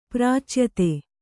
♪ prācyate